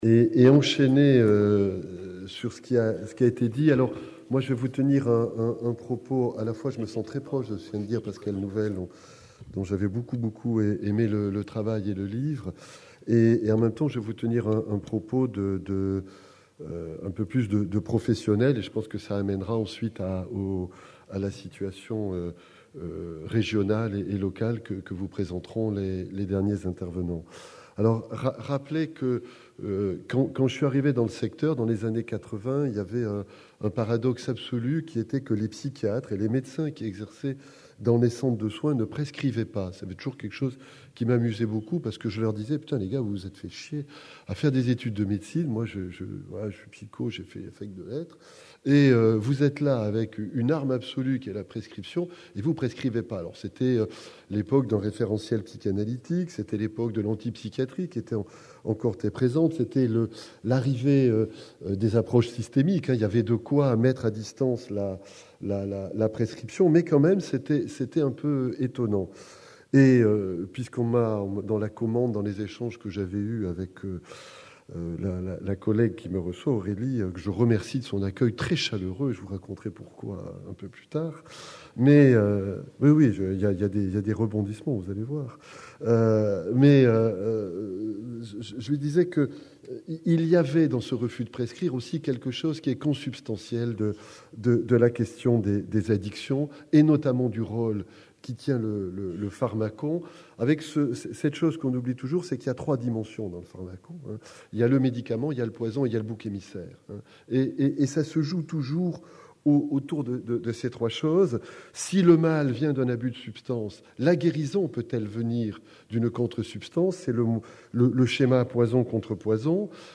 CNIPsy 2010 Marseille : 7ème Congrès National des Internes en Psychiatrie (CNIPsy).